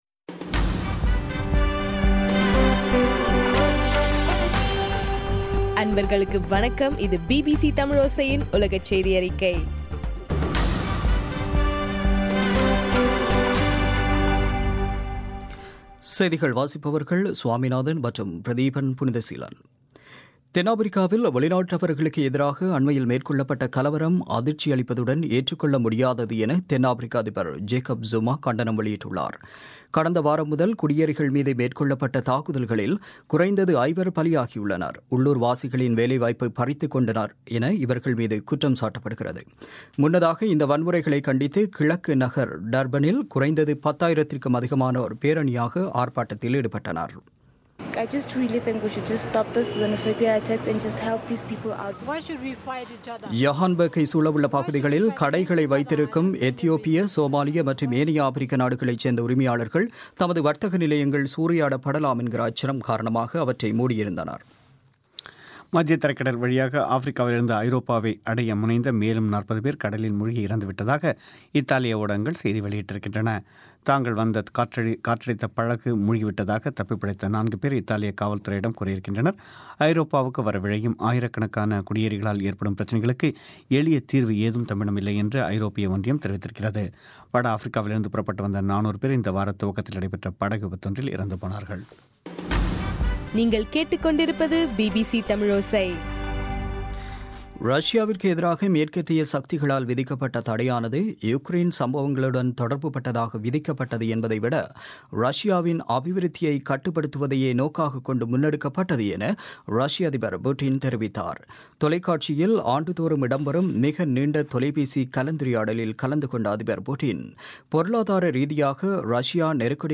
இன்றைய (ஏப்ரல் 16) பிபிசி தமிழோசை செய்தியறிக்கை